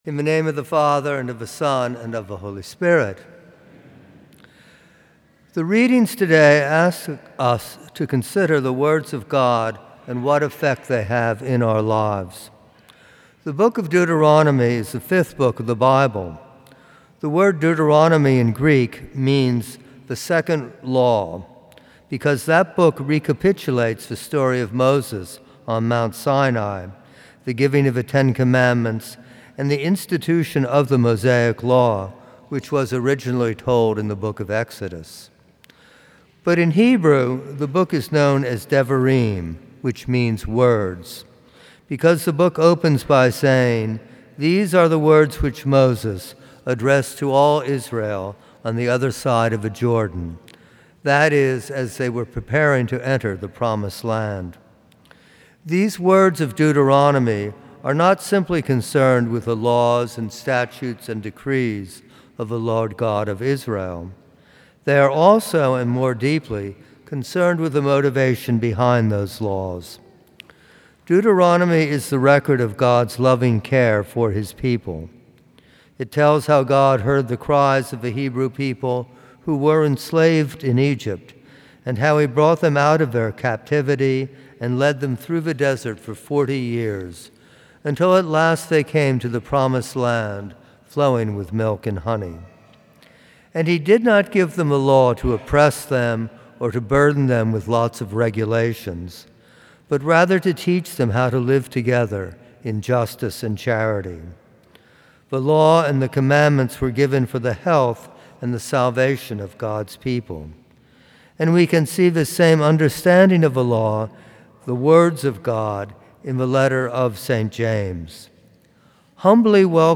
From Series: "Homilies"